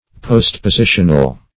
Postpositional \Post`po*si"tion*al\, a.